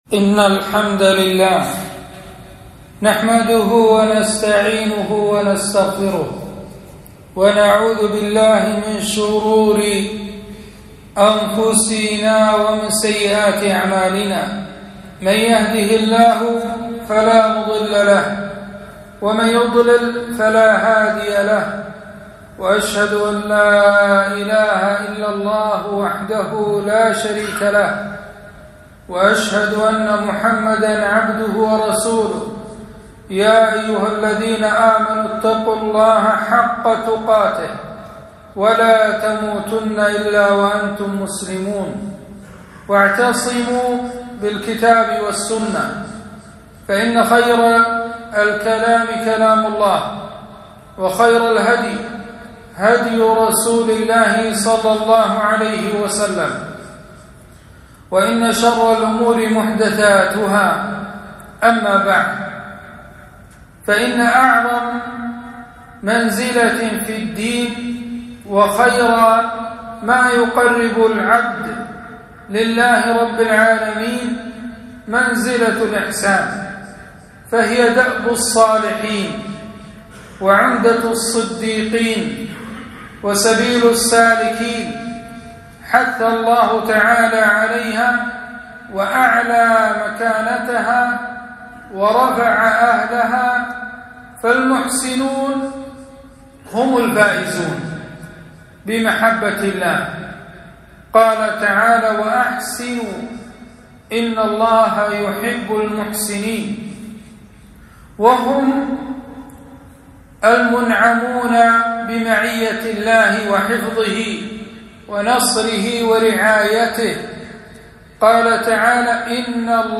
خطبة - (وأحسنوا إن الله يحب المحسنين)